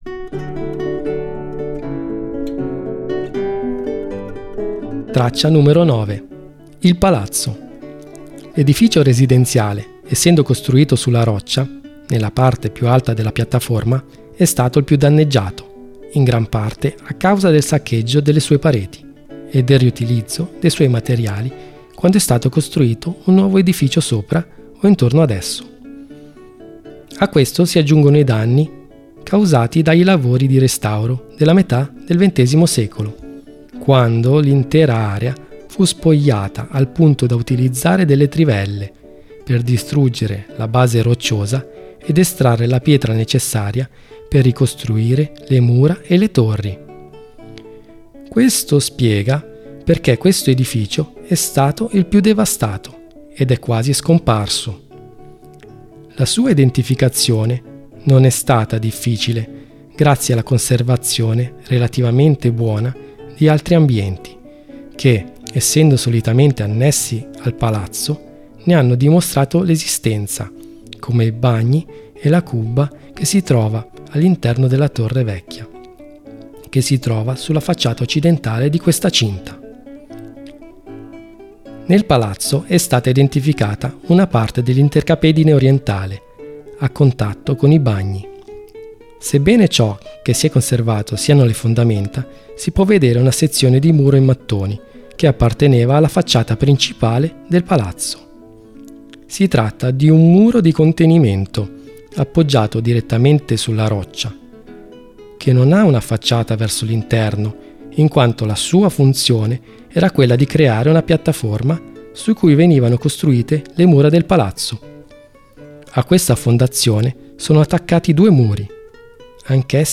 Audioguida Castello di Salobreña